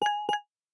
biometric_register_progressing.ogg